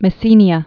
(mĭ-sēnē-ə, -sēnyə)